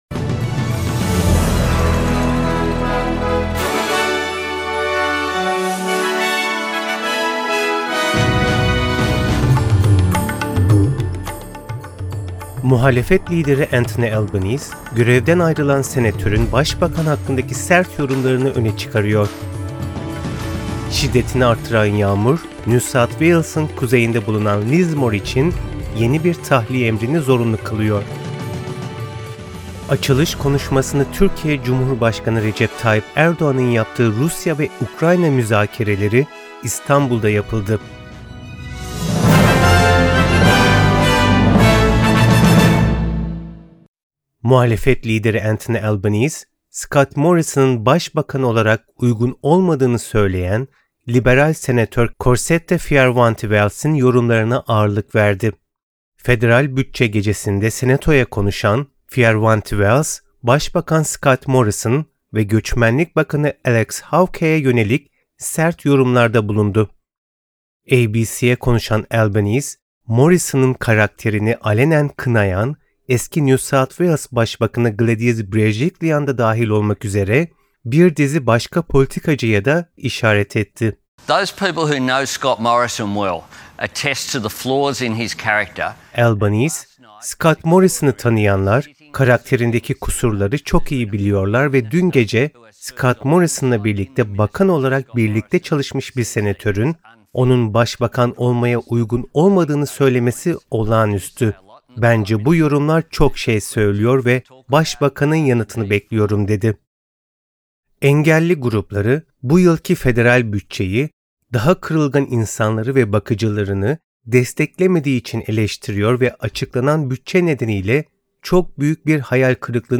SBS Türkçe’den Avustralya, Türkiye ve dünyadan haberler.